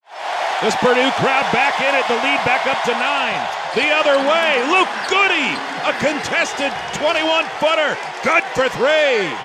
NCAA Men's Basketball Compass Play-by-Play Highlights